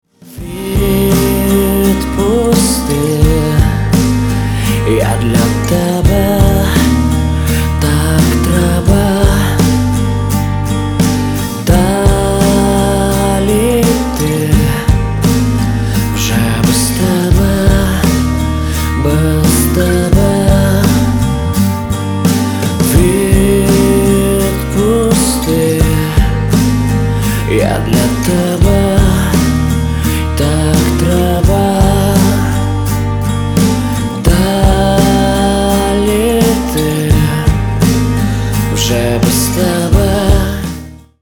• Качество: 320, Stereo
мужской вокал
Alternative Rock
украинский рок
grunge
баллады